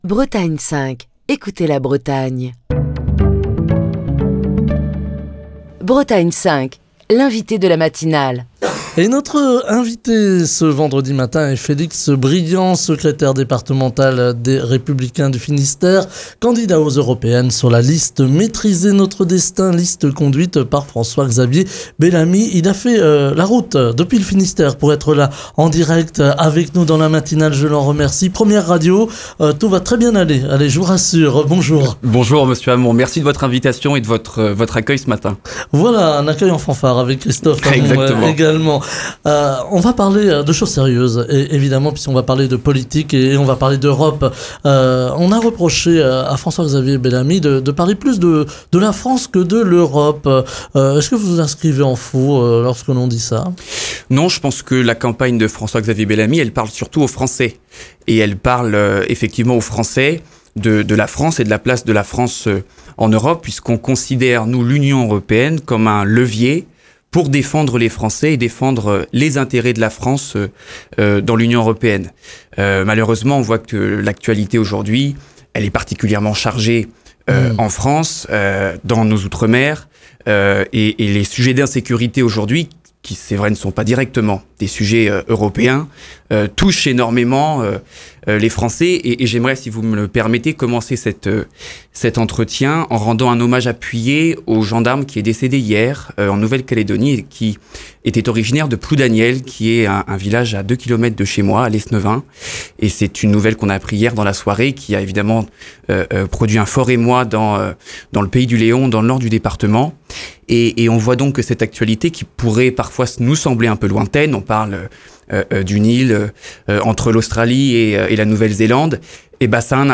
Ce vendredi, l'invité politique de la matinale de Bretagne 5